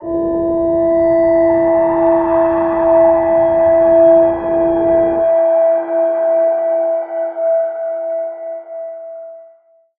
G_Crystal-E5-pp.wav